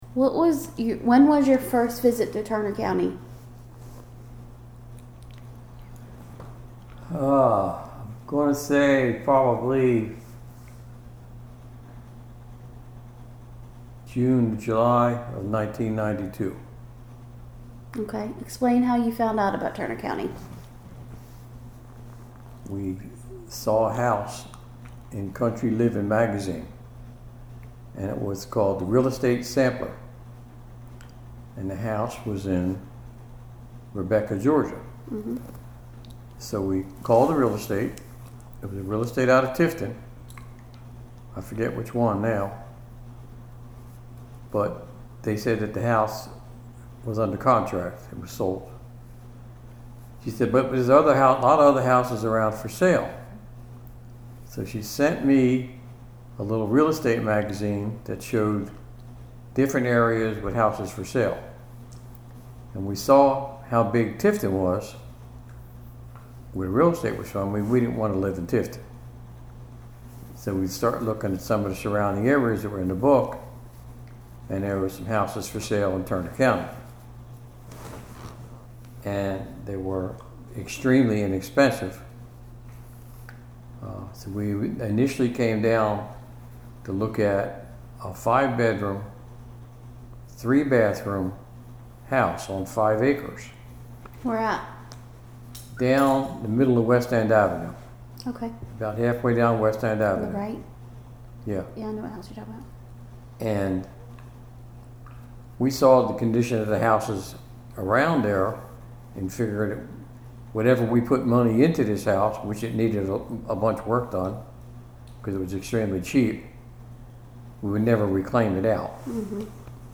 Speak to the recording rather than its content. Sycamore, Georgia